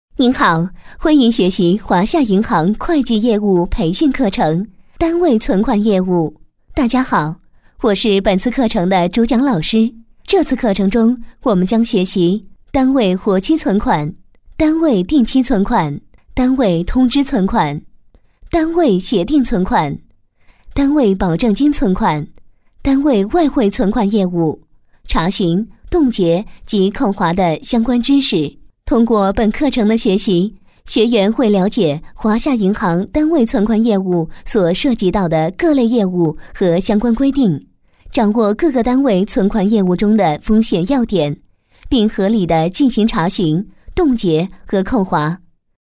专题片宣传片配音作品在线试听-优音配音网